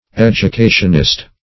Educationist \Ed`u*ca"tion*ist\, n.